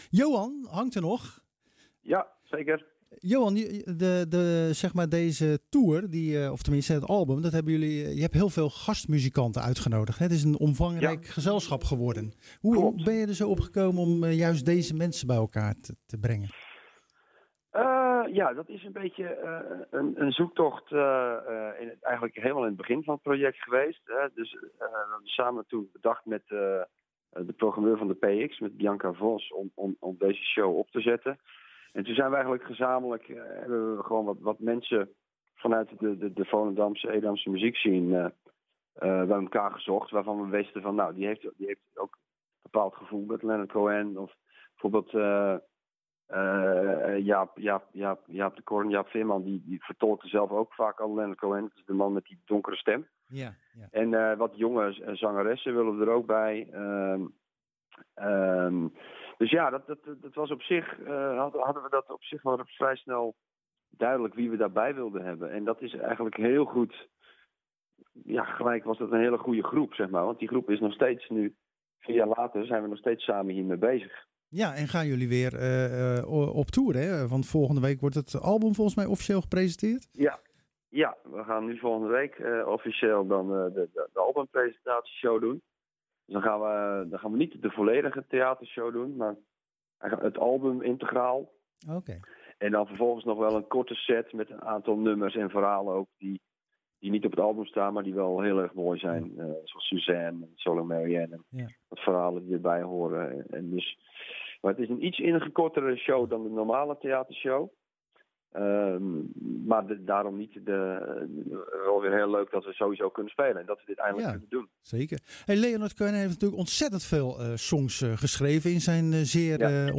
Frontman